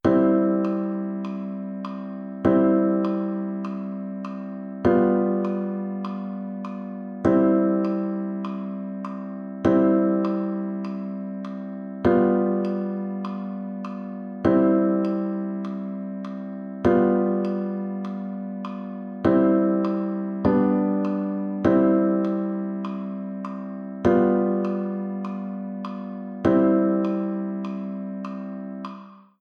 In der linken Hand wollen wir von Anfang an dreistimmige Akkorde spielen.
Wenn du dir unser erstes Lied(chen) mal genau anschaust, fallen dir sicher die Großbuchstaben C und G7 über den Noten auf?